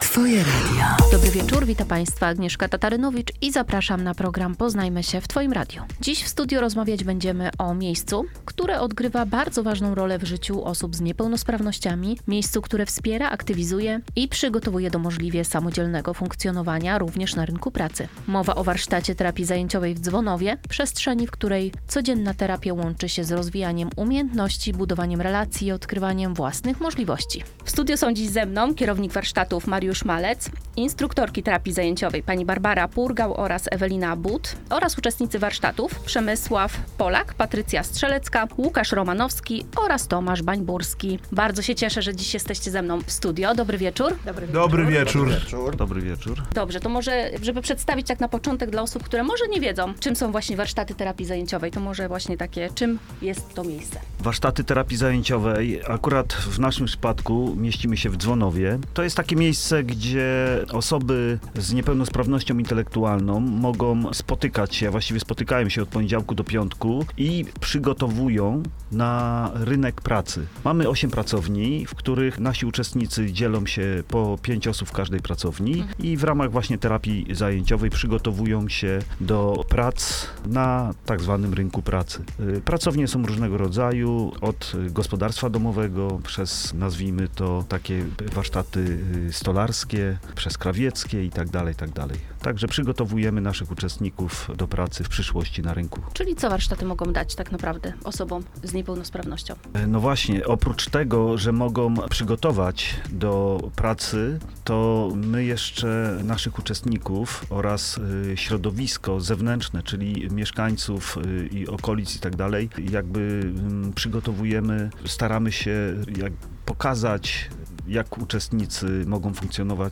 Gośćmi programu będą przedstawiciele kadry oraz uczestnicy warsztatów.